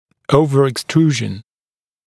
[ˌəuvəeks’truːʒn][ˌсупрэи’рапшн]чрезмерная экструзия, перепрорезывание